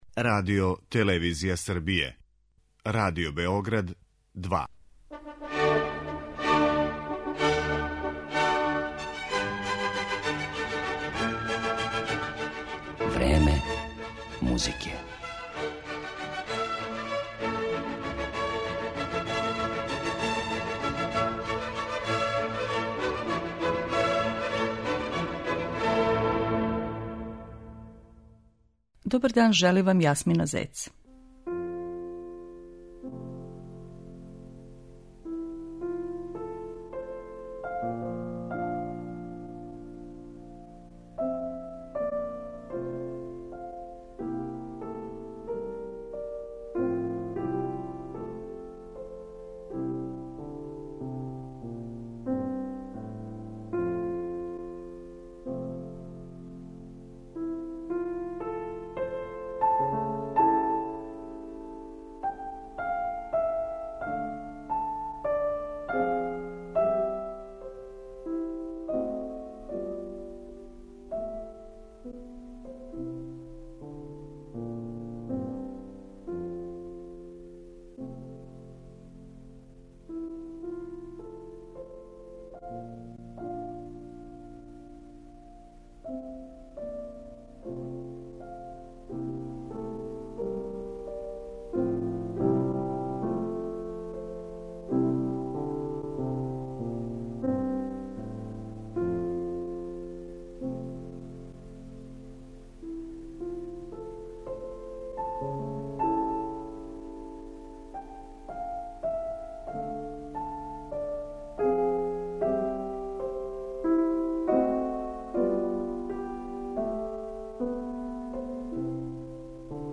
Емисију Време музике посвећујемо славном немачком пијанисти, камерном музичару и диригенту Кристофу Ешенбаху, кога ћемо представити као изврсног интерпретатора композиција Франца Шуберта, Роберта Шумана и Волфганга Амадеуса Моцарта.